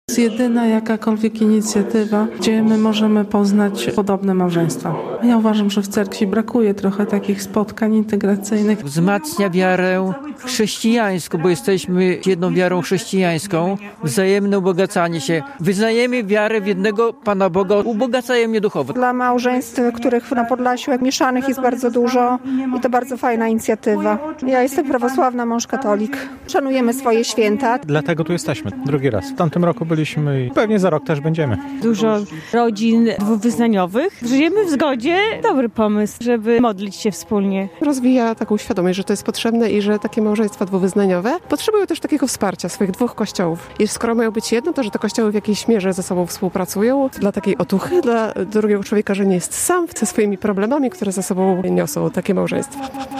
Polskie Radio Białystok